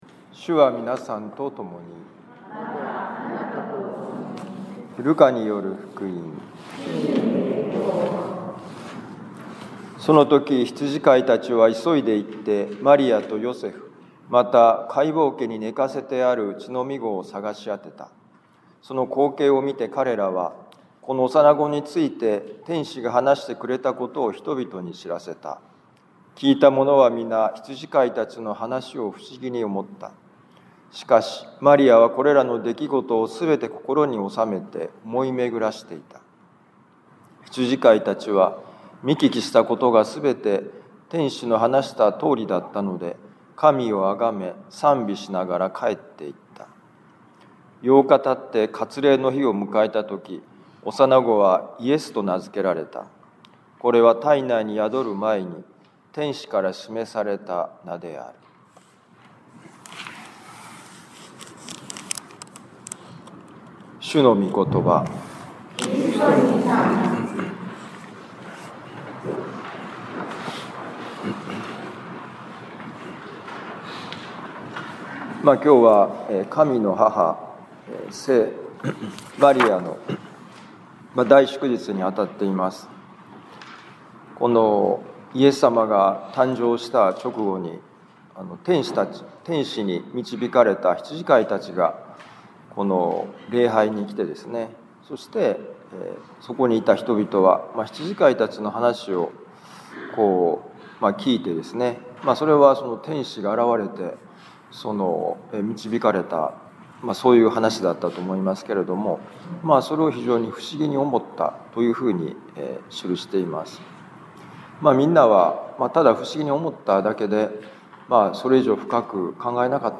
【ミサ説教】
ルカ福音書2章16-21節「おそれを超えて信じる心」2026年1月1日神の母聖マリアのミサ 防府カトリック教会